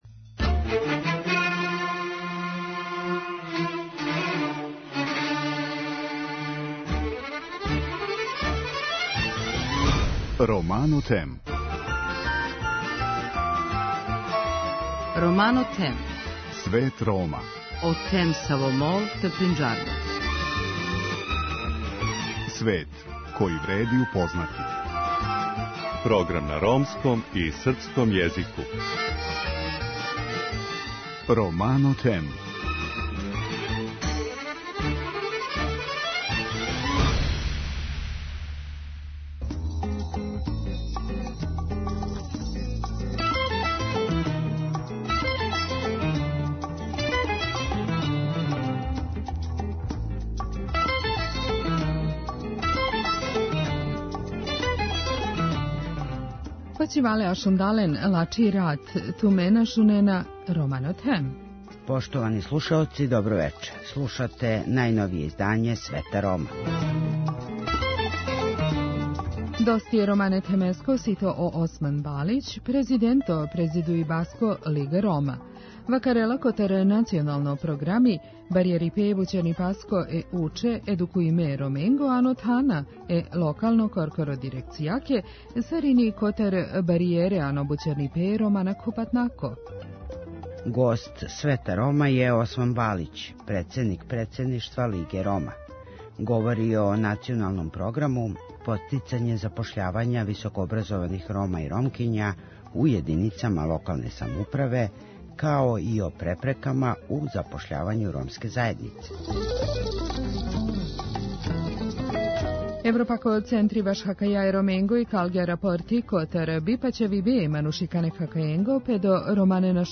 Гост емисије